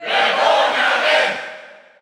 File:Villager Female Cheer German SSBU.ogg
Category: Crowd cheers (SSBU) You cannot overwrite this file.
Villager_Female_Cheer_German_SSBU.ogg